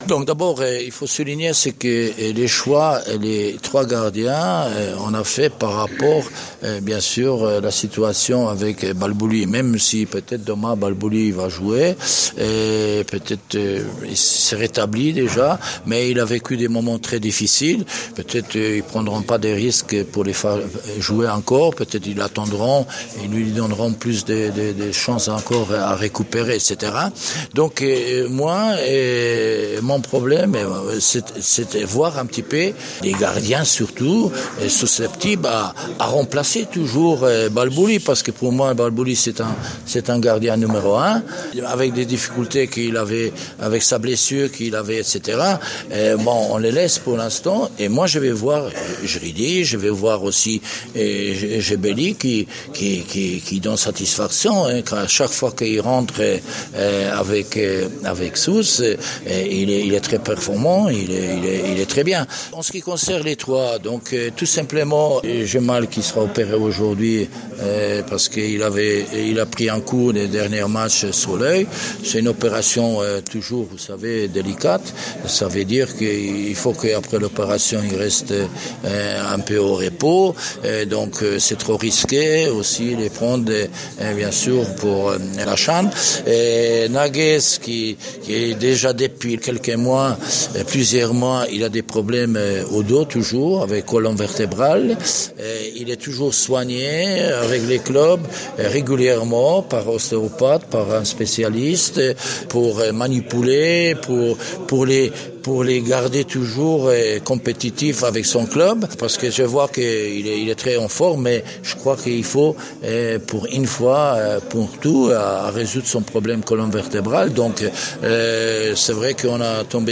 هنري كسبرجاك : مدرب المنتخب التونسي